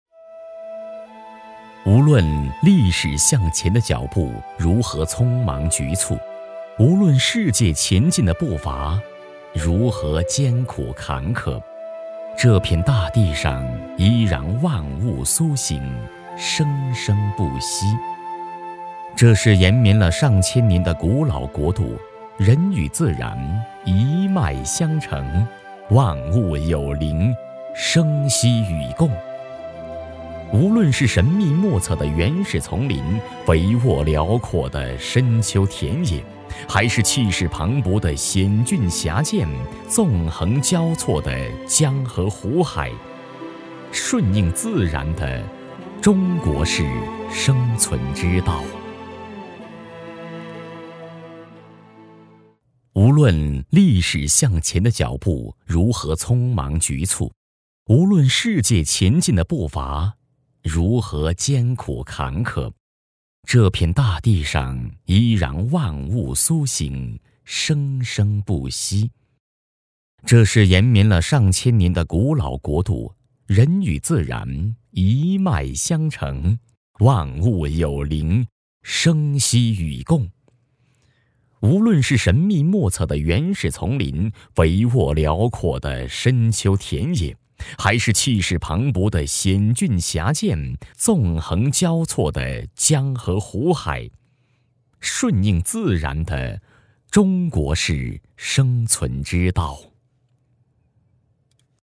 人文历史纪录片配音
男国286_纪录片_人文历史_渔船人家.mp3